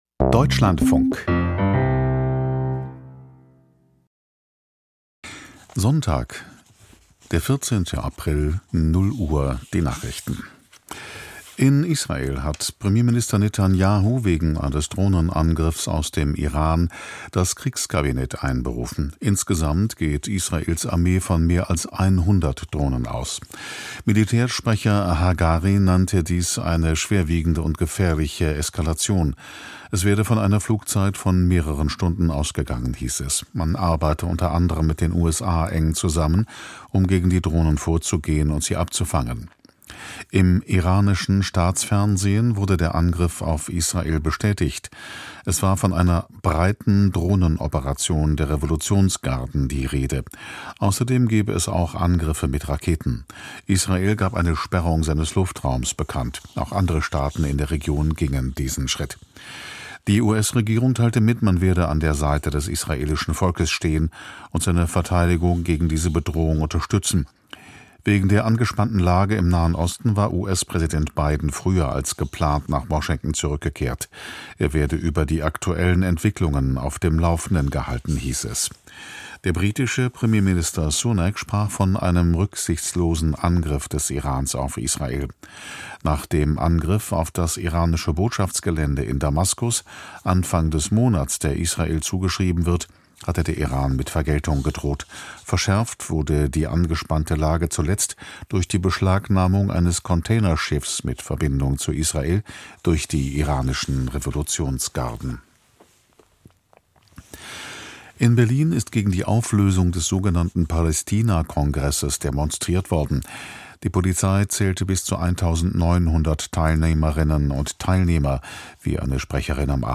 Studie zeigt Wirksamkeit von Malaria-Impfstoff R21: Interview